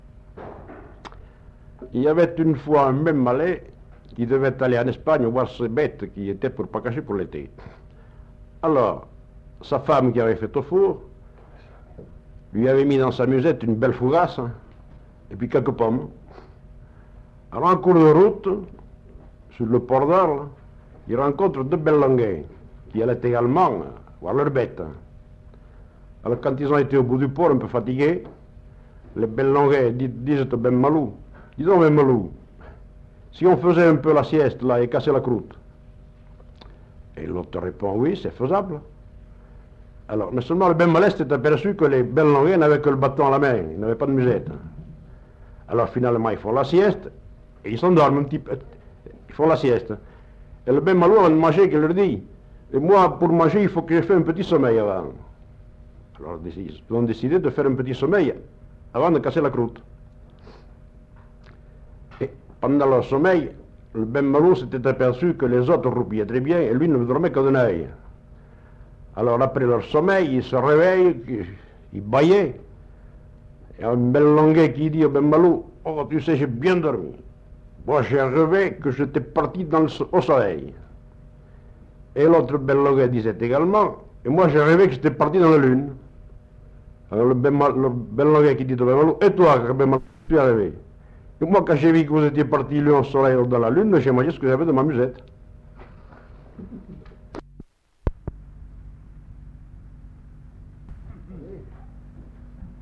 Aire culturelle : Couserans
Genre : conte-légende-récit
Type de voix : voix d'homme Production du son : parlé